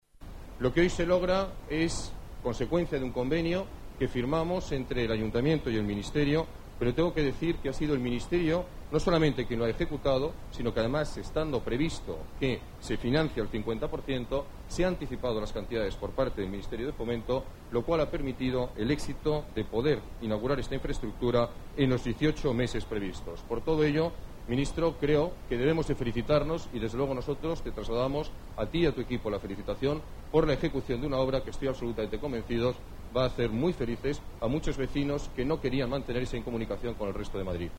Nueva ventana:Declaraciones del alcalde